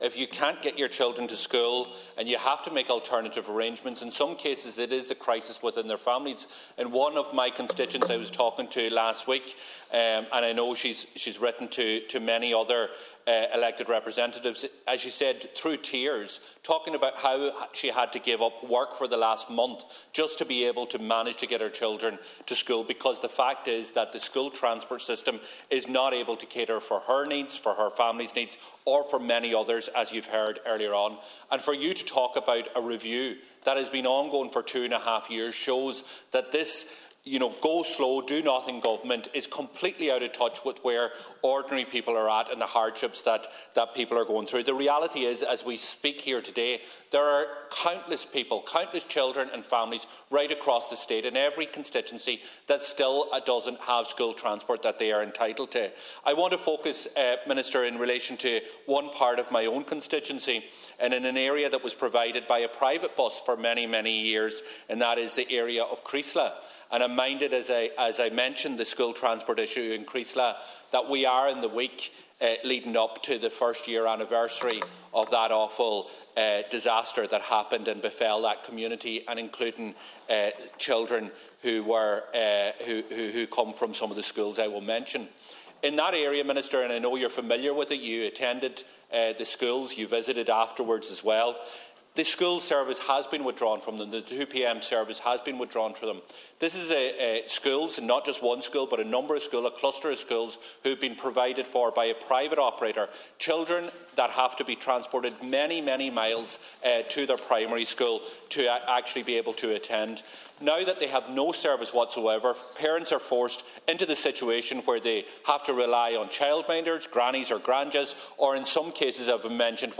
The Dail has heard how some parents in Donegal are being forced to give up work to ensure their children make it to school.
Donegal Deputy Pearse Doherty urged Education Minister Norma Foley to ensure measures are in place so children can avail of public school transport that they are entitled to.